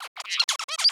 Songs consist of brief bursts of sound (syllables) separated by silence (intervals).
By doubling the length of silence between two syllables in a motif, a change is being made to the overall rhythm and timing of song.
The first one shows a doubling of the first interval, and the second one shows a reversal of the last syllable (marked by asterisks).
LISTEN: increased interval
motif-interval-increase.wav